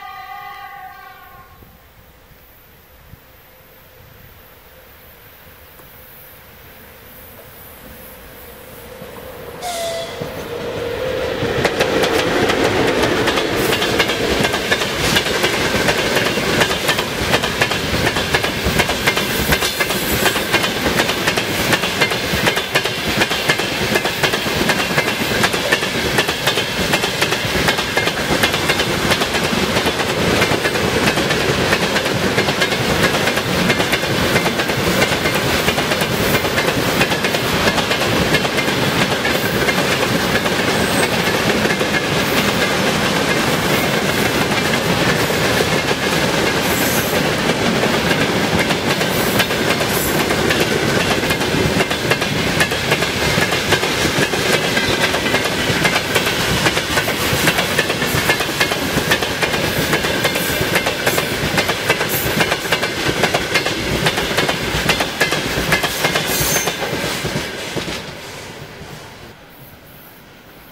train.ogg